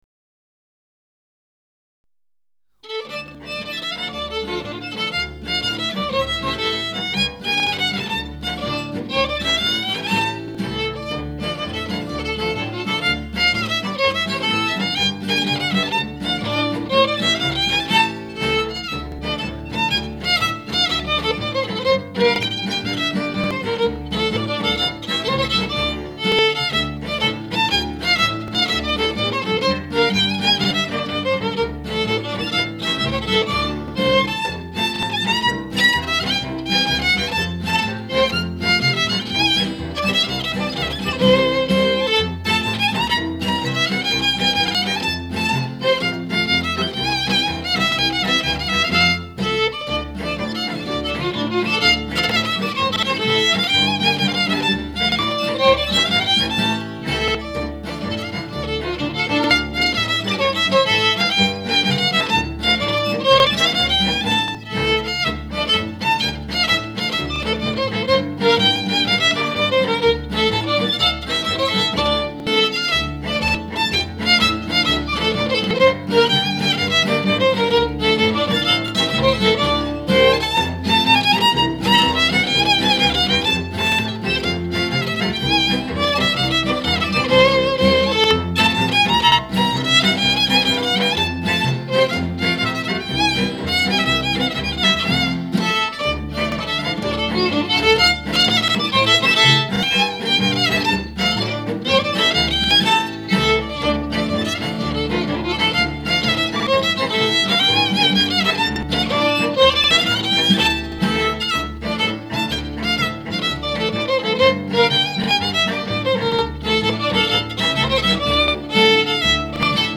Manfrina di Castelbolognese       ballo